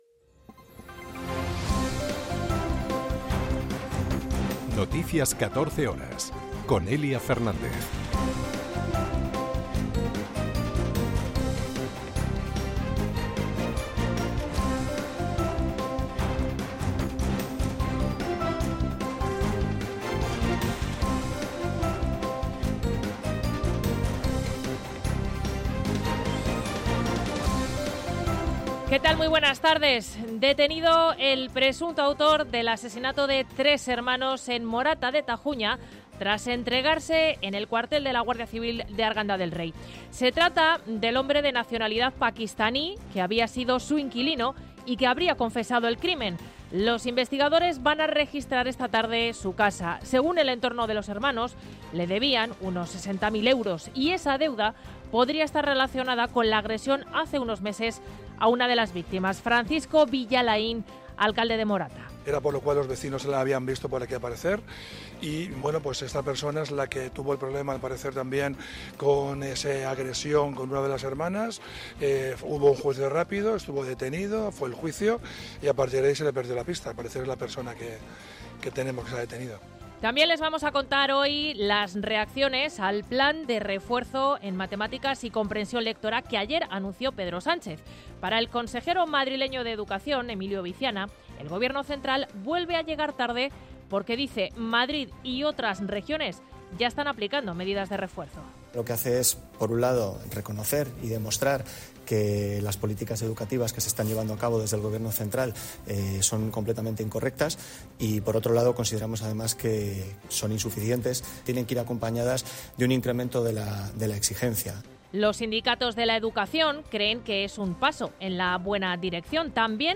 Noticias 14 horas 22.01.2024